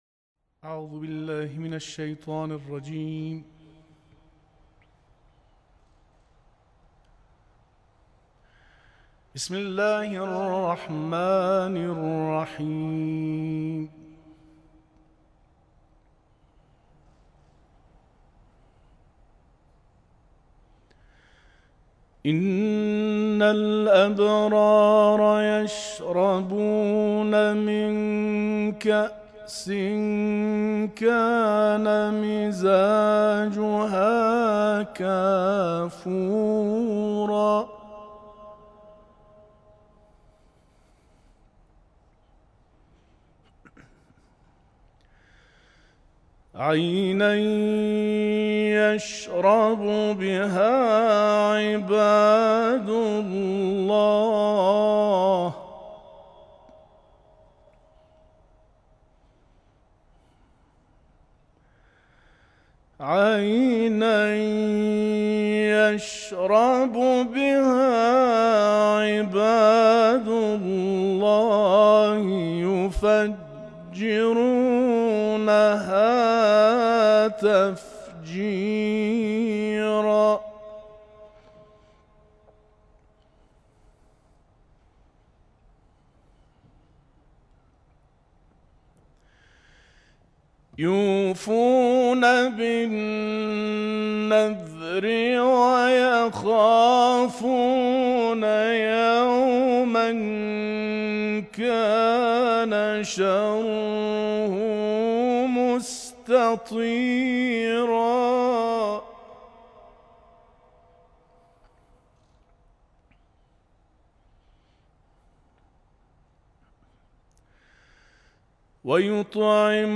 اولین شب مراسم عزاداری ایام شهادت حضرت فاطمه‌زهرا سلام‌الله‌علیها
قرائت